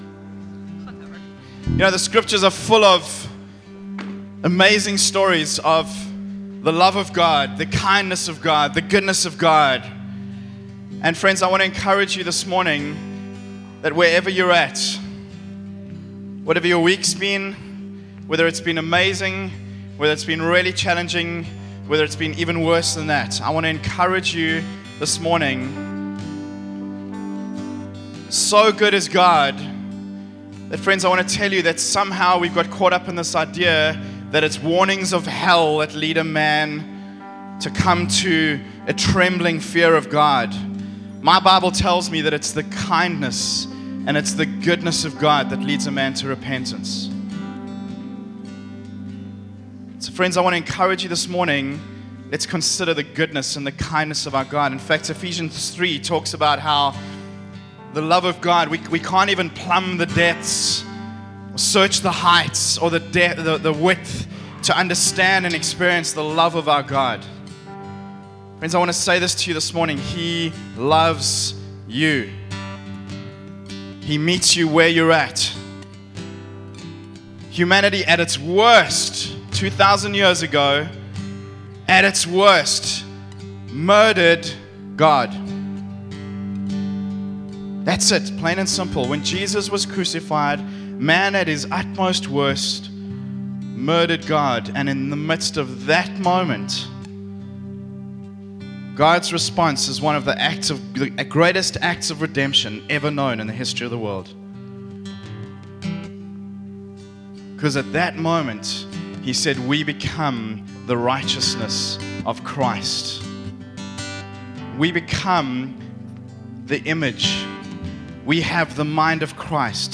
Valued as Treasure - Sermons - Oceanside Church -